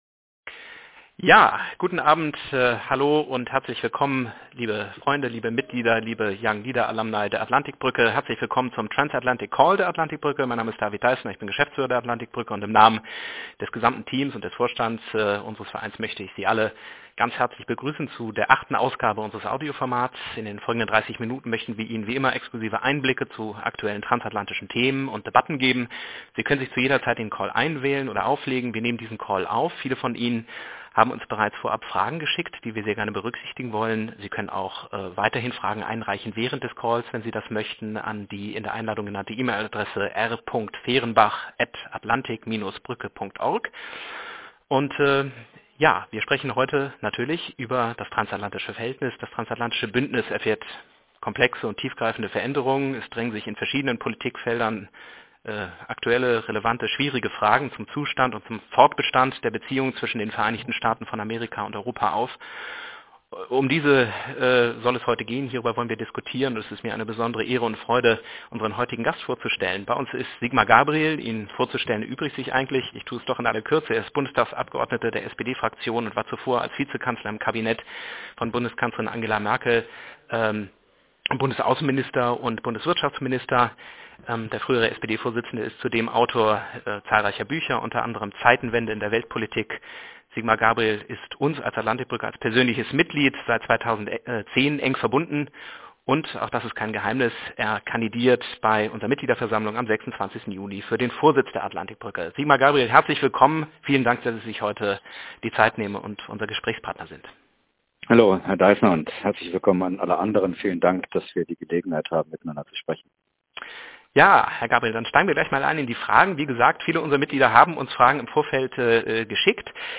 Der ehemalige Bundesaußenminister spricht im Transatlantic Call der Atlantik-Brücke über die deutsche Verantwortung in der Weltpolitik, Nord Stream 2 und die geostrategische Herausforderung durch China.